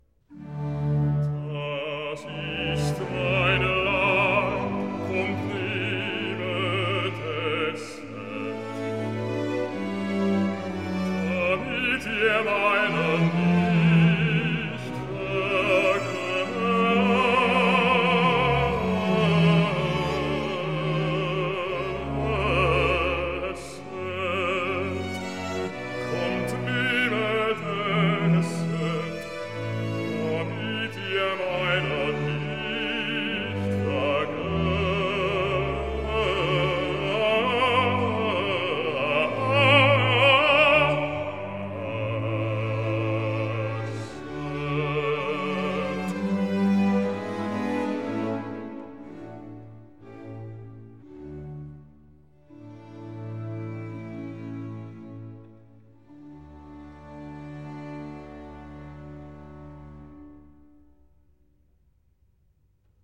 Accompagnato